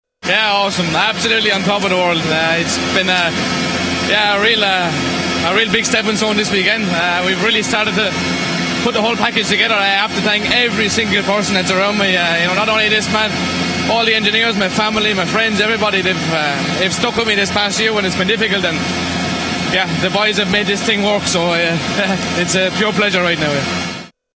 Speaking immediately after crossing the finish line, Breen was understandably thrilled with the week’s work.